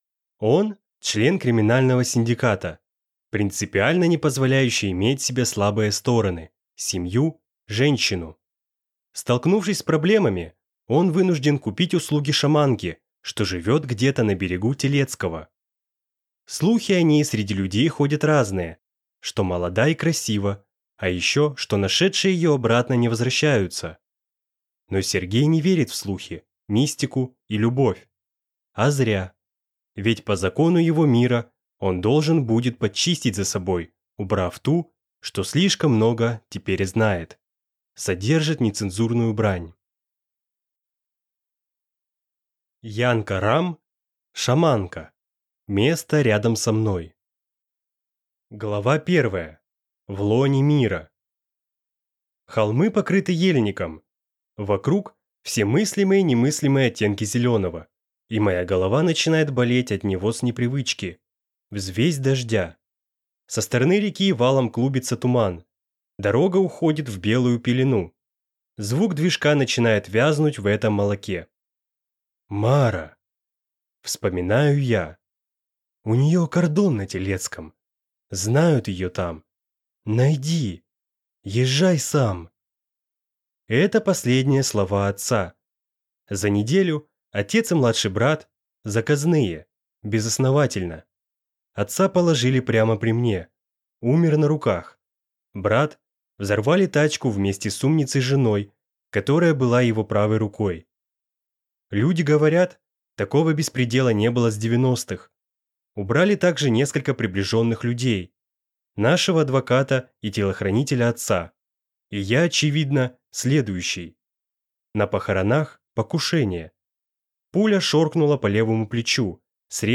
Аудиокнига Шаманка. Место рядом со мной | Библиотека аудиокниг